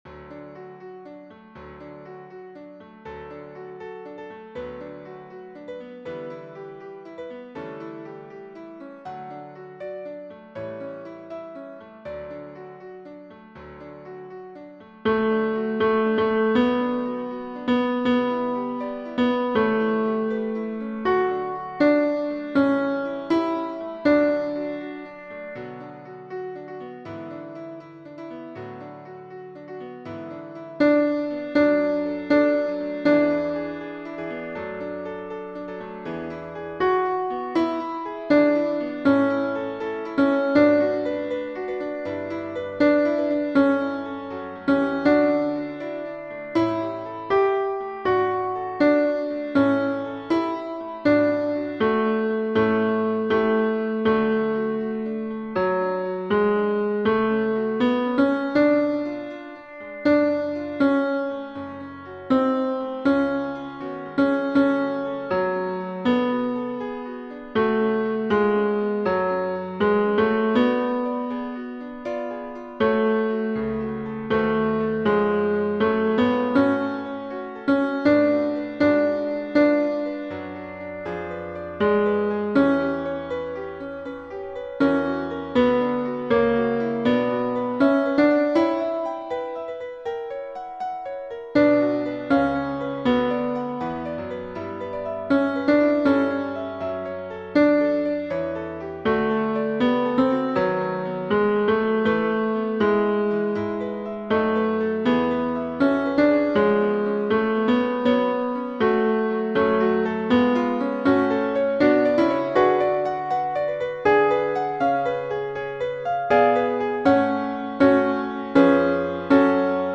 He_Watching_Over_Israel__Felix_Mendelssohn_Tenor.mp3